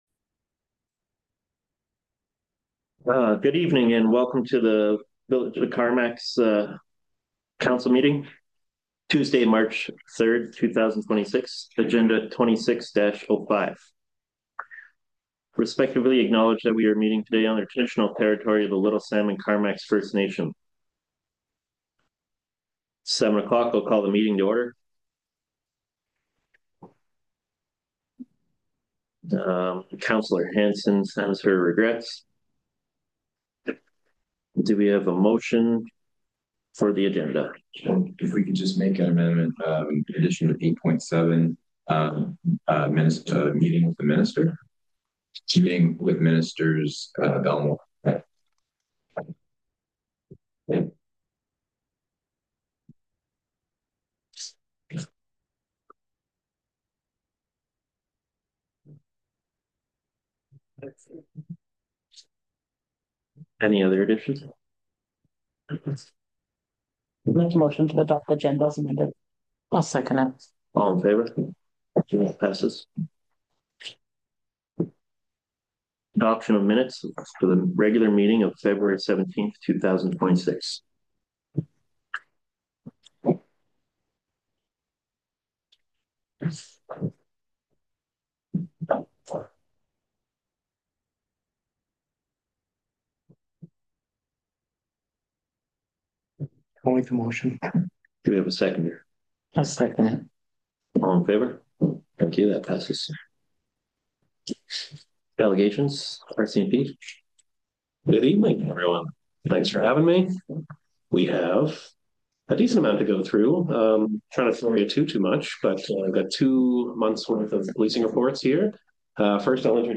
26-07 Council Meeting